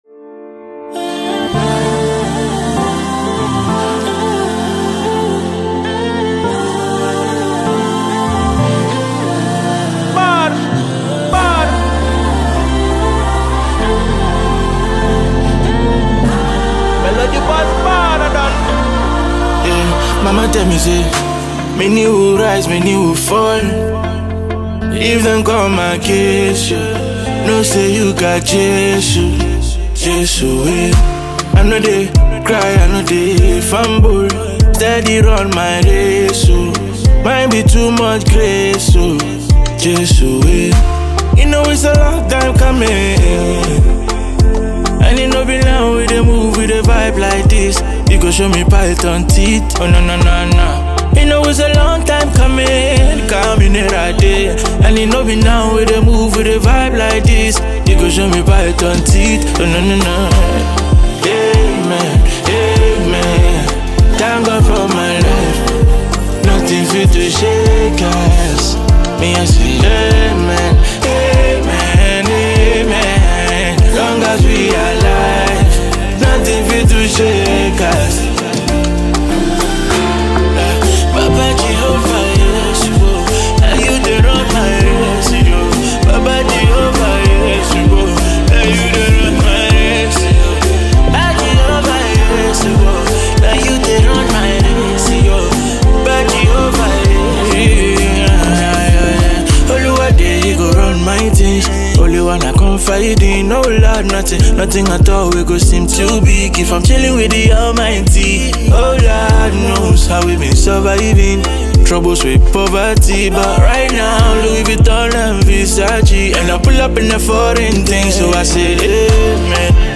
Emerging Ghanaian Afrobeat
brings emotion, melody, and introspection